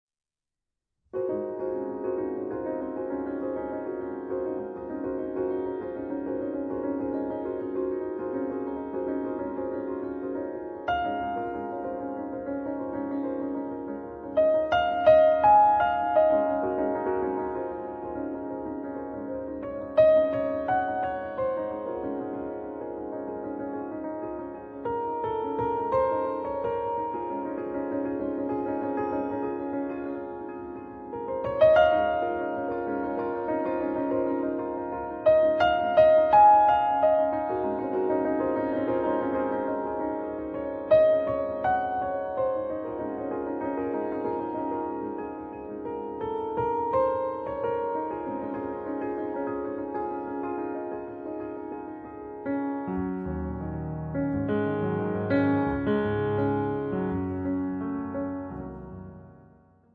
pianoforte
illustra la sua profondità timbrica